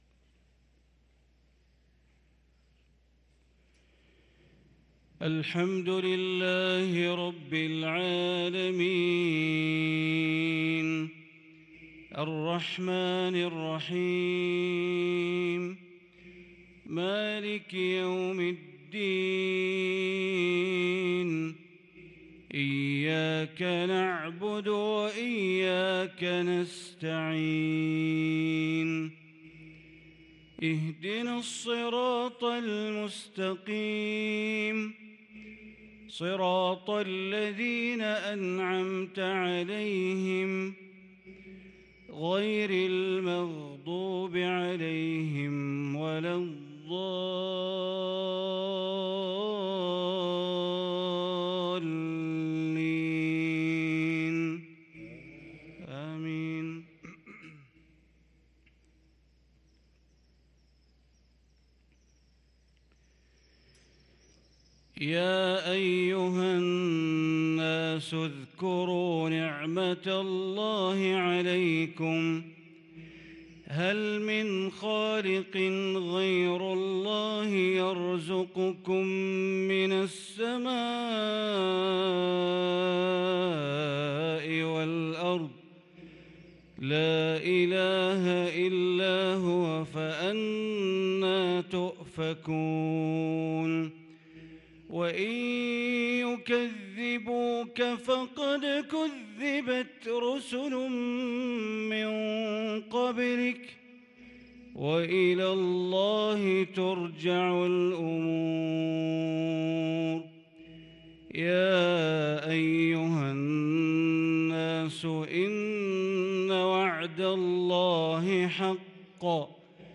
صلاة المغرب للقارئ بندر بليلة 18 جمادي الآخر 1443 هـ
تِلَاوَات الْحَرَمَيْن .